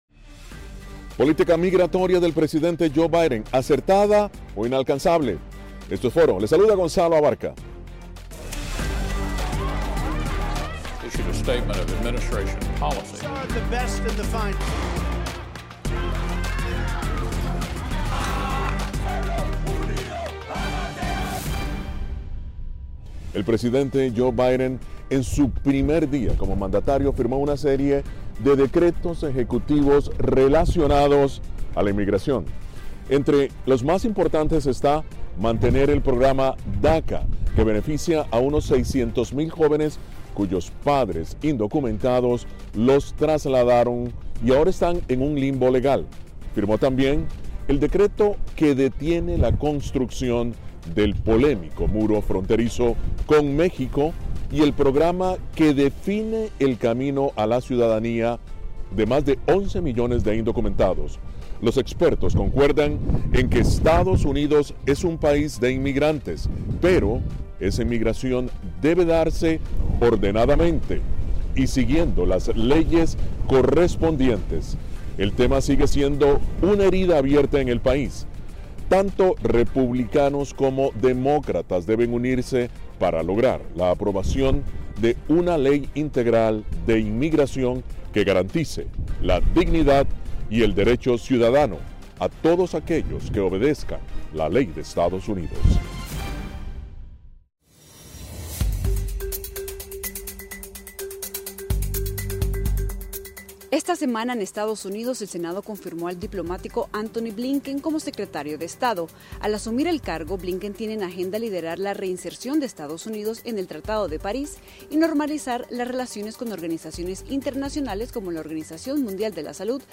abogada de inmigración